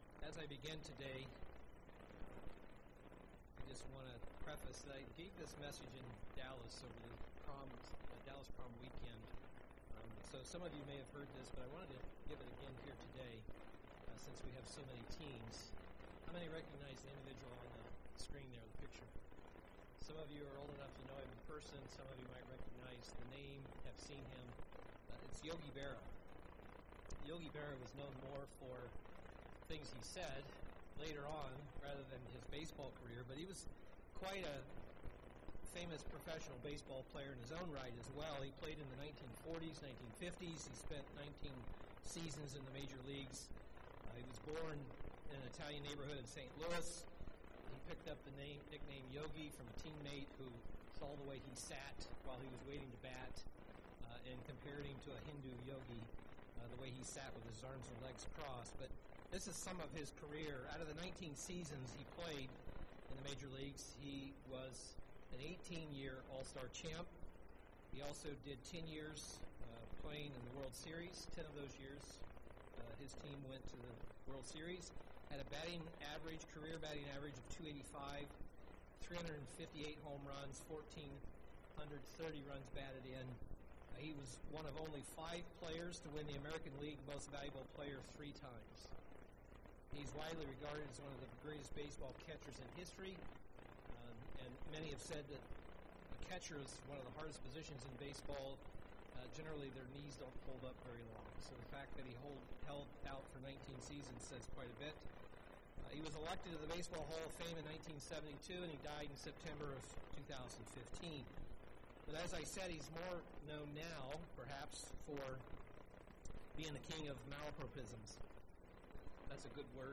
Given in Milwaukee, WI
UCG Sermon decision making God's Will Studying the bible?